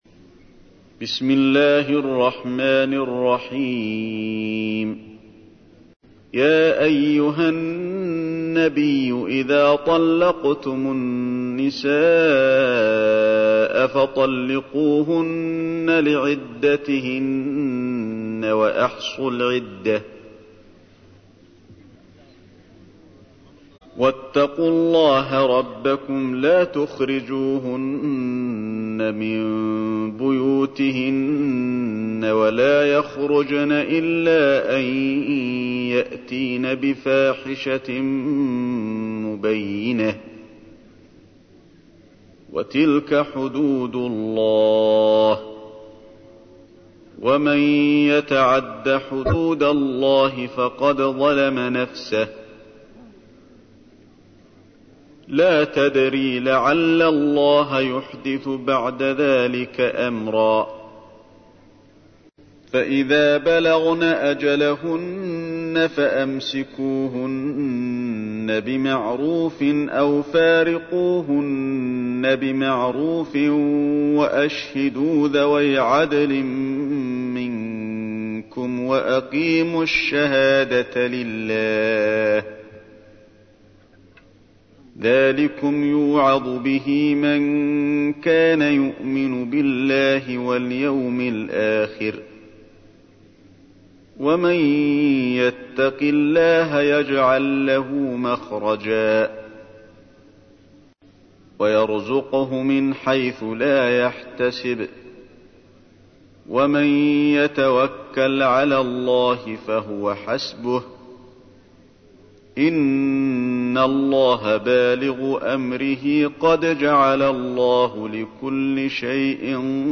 تحميل : 65. سورة الطلاق / القارئ علي الحذيفي / القرآن الكريم / موقع يا حسين